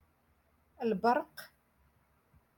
Moroccan Dialect-Rotation Six- Lesson Nineteen